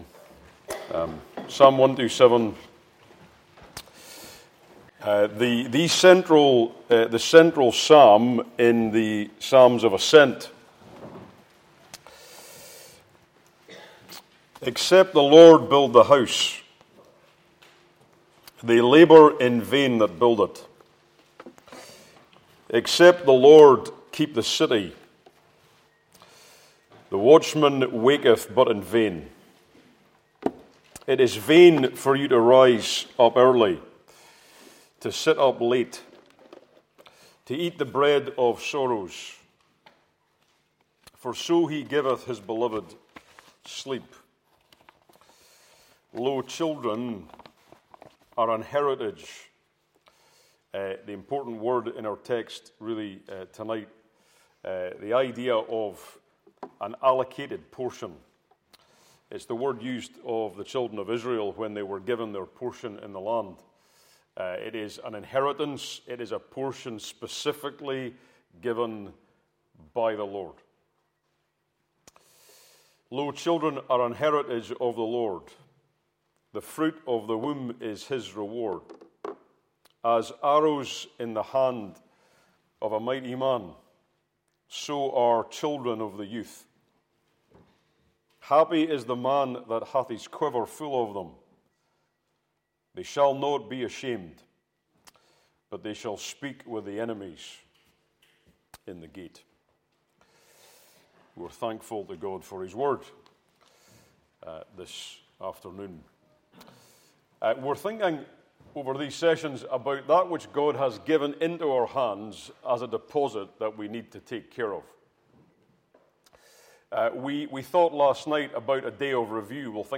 Passage: Psalm 127, Ephesians 6, Meeting Type: Ministry